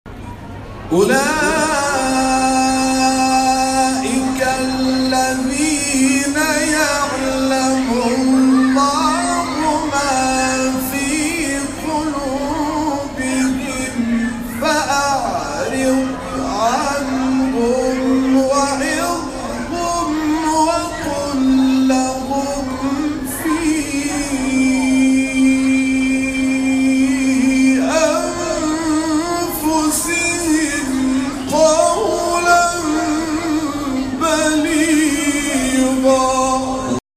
گروه جلسات و محافل: کرسی تلاوت رضوی همزمان با ایام دهه کرامت و ولادت حضرت علی بن موسی الرضا(ع) در مسجد سیدالشهداء شهر اراک برگزار شد.